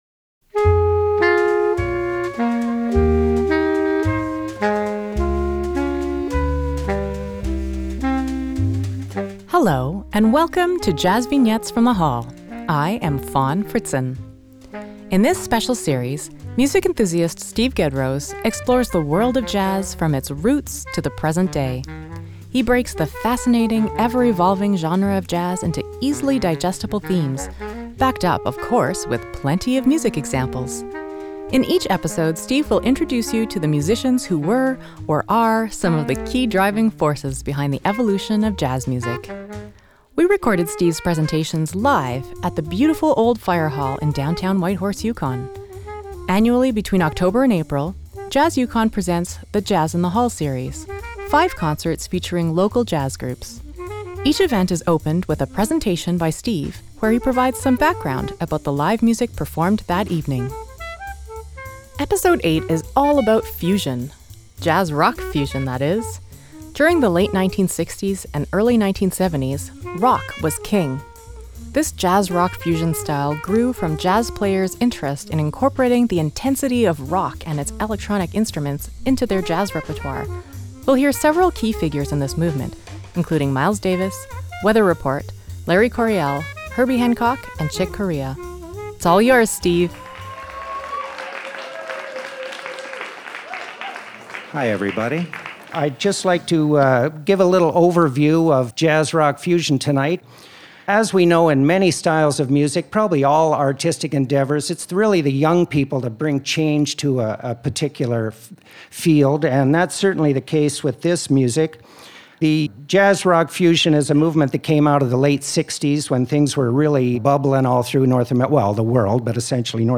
Jazz-Rock Fusion Download
JVFTH08JazzRockFusion.mp3 57,845k 256kbps Stereo Comments